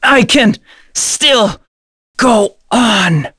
Gladi-Vox_Dead.wav